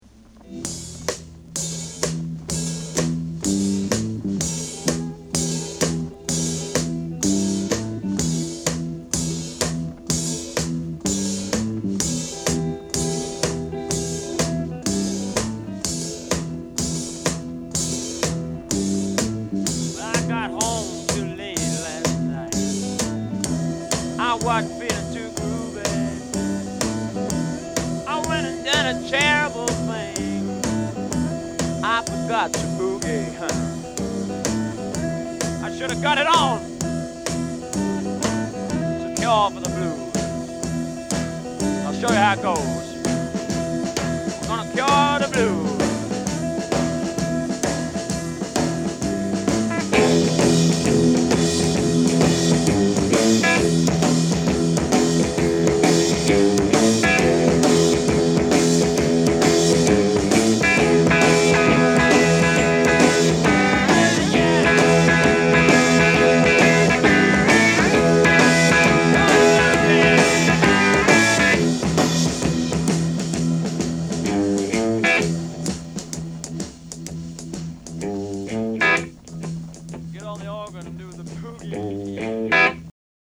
BLUES ROCK 帯付き。
盤は薄い擦れや僅かですが音に影響がある傷がいくつかある、使用感が感じられる状態です。